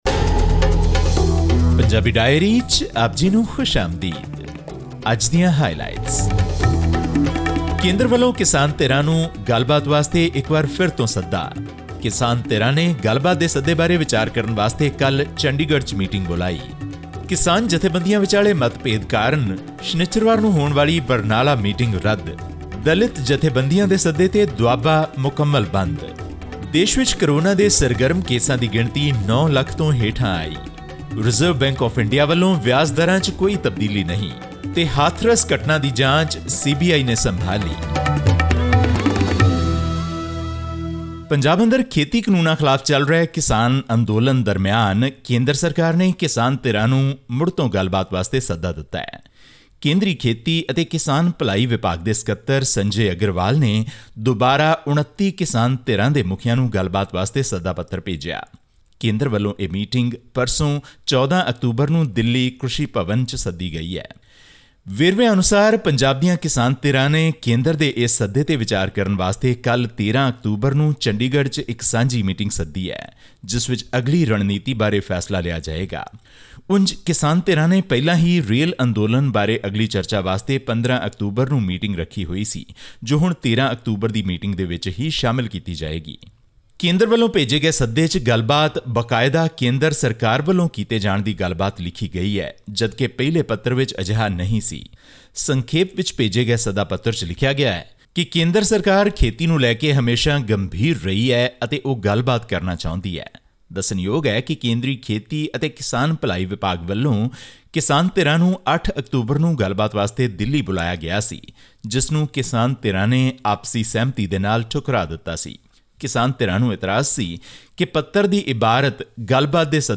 In this week's news wrap from the Indian state of Punjab, we bring you updates on the farmers' protests, coronavirus data and more.